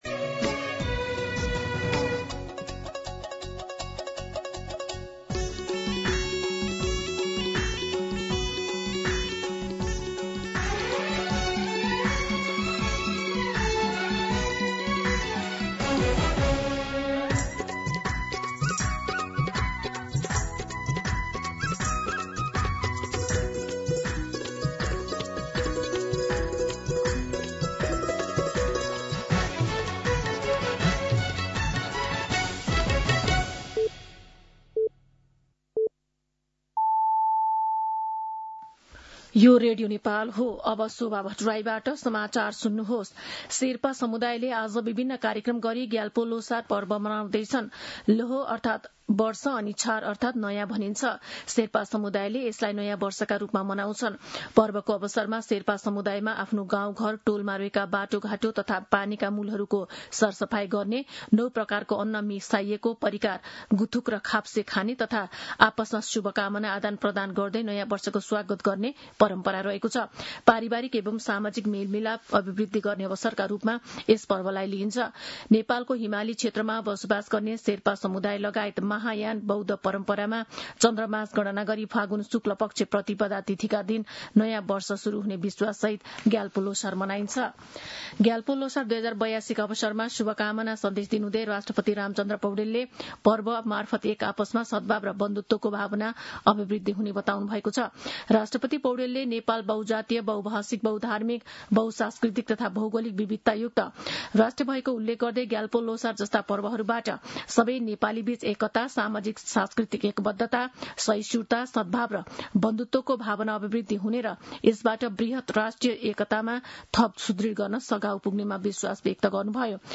मध्यान्ह १२ बजेको नेपाली समाचार : ६ फागुन , २०८२
12-pm-Nepali-News-3.mp3